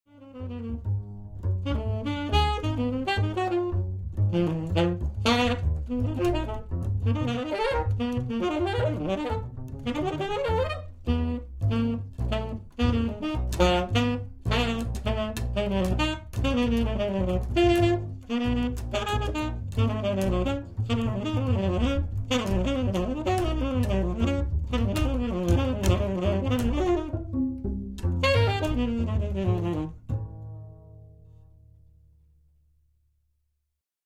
saxophone
bass